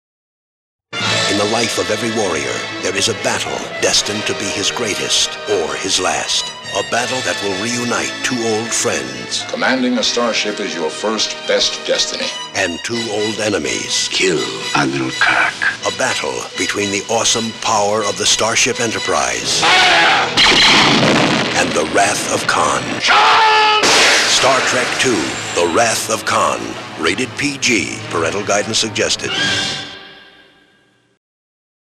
Radio Spots
The spots are very good and capture the action and mood of the movie.